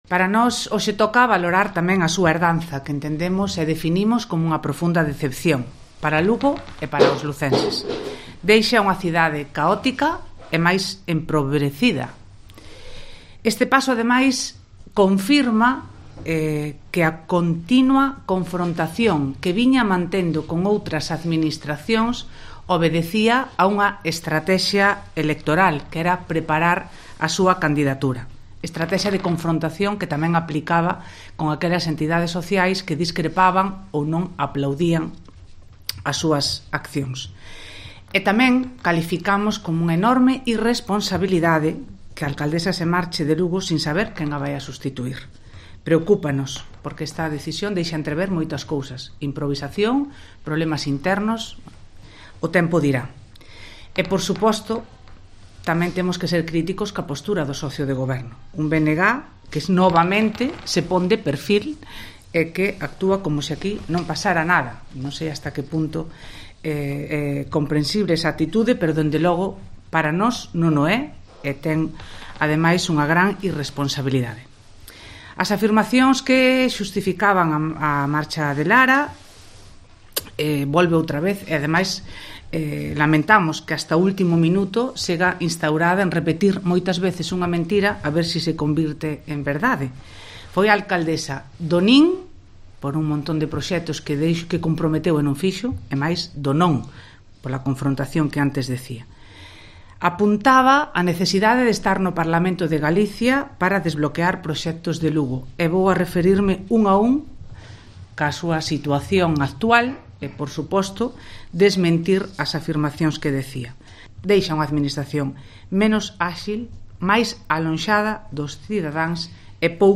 La portavoz del grupo municipal del Partido Popular en el Concello de Lugo -y presidenta provincial del partido-, Elena Candia, afirmó este martes en rueda de prensa que la renuncia de Lara Méndez a la Alcaldía no fue "una sorpresa del todo", porque "que no estaba a gusto y pedía salir era un rumor que cobraba cada día más fuerza" en el ámbito municipal.